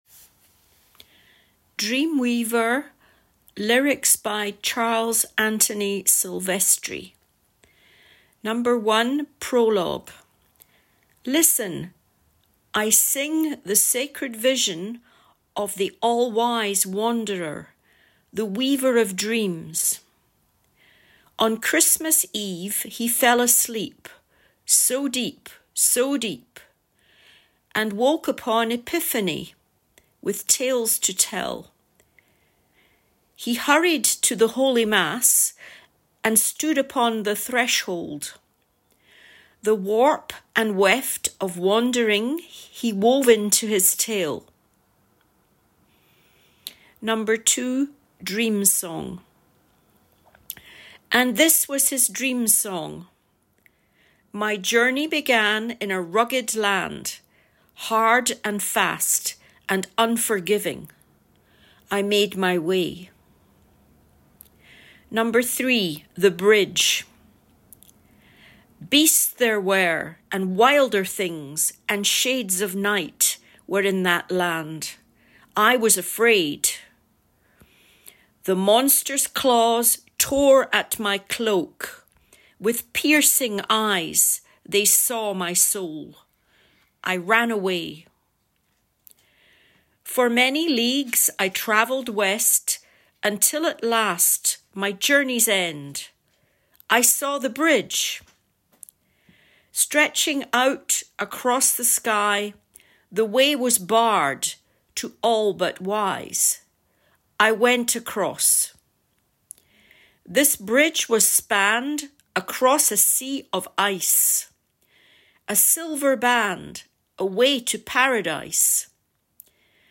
Dreamweaver-prononciation.mp3